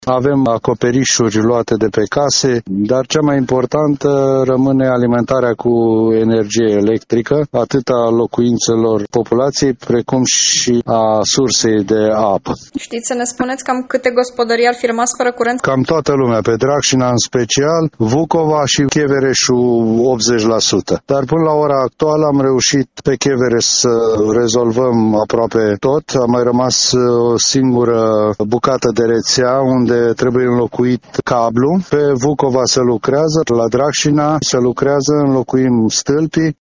Primarul Marcel Muia a mers pe teren, pentru a evalua pagubele.